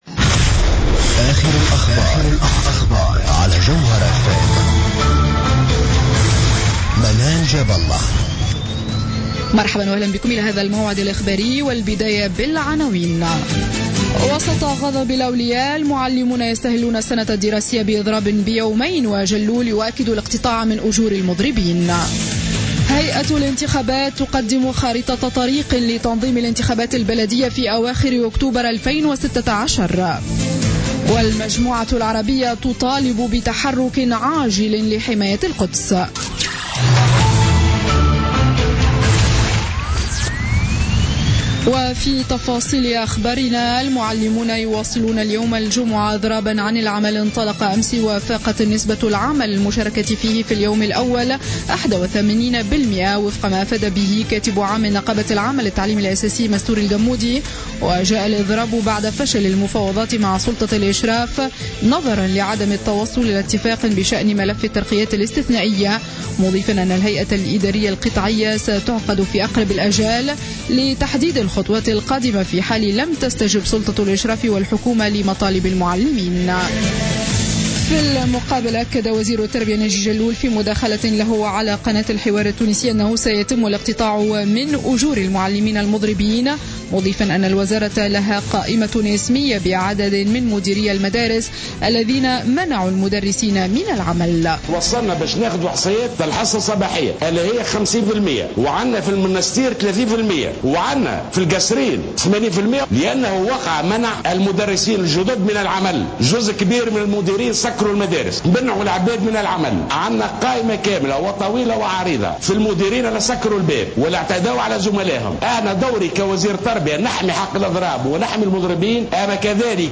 نشرة أخبار منتصف الليل ليوم الجمعة 18 سبتمبر 2015